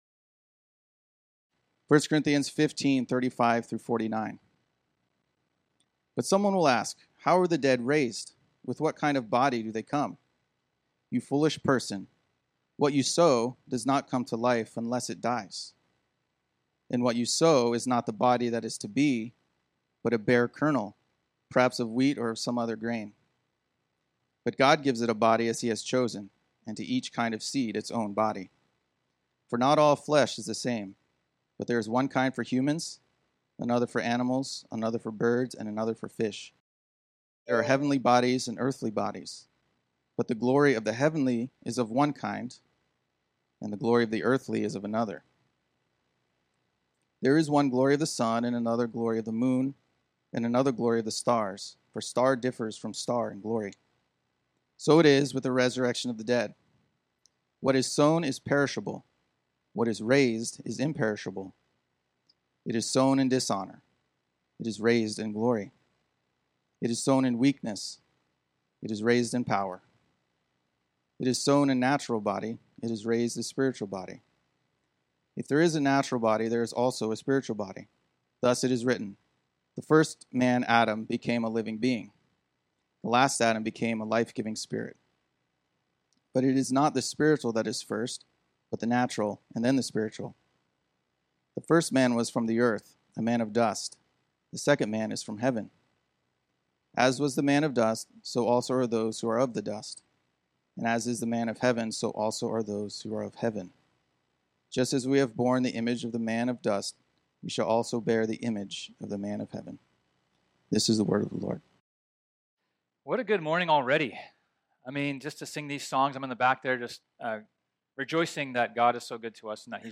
This sermon was originally preached on Sunday, April 10, 2022.